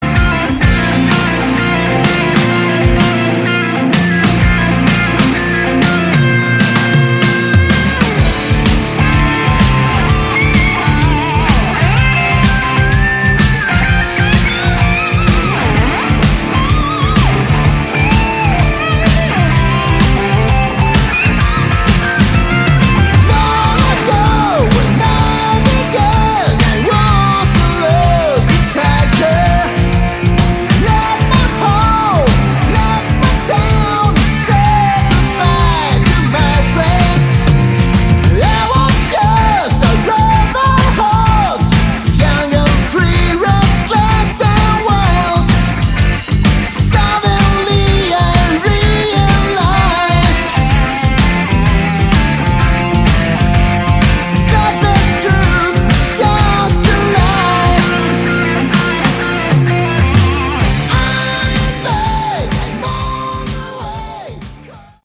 Category: Hard Rock
guitar
bass
lead vocals
drums